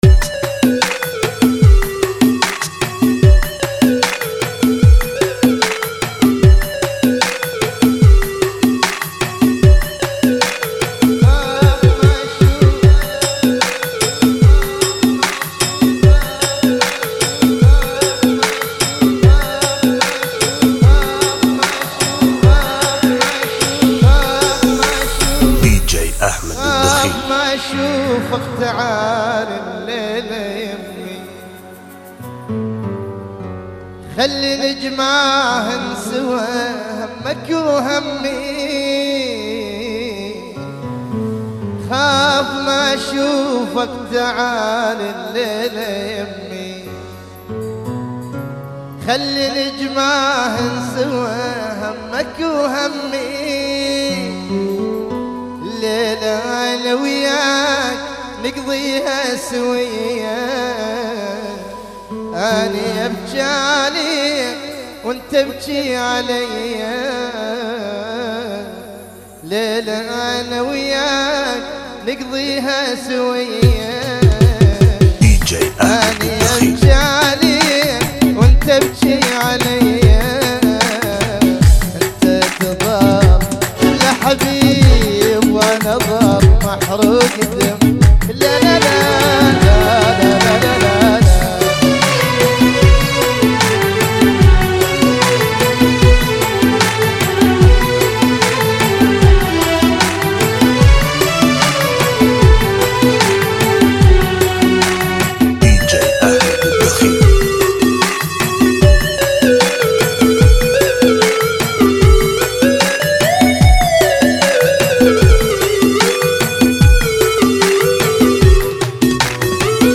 ريمكس
Funky Remix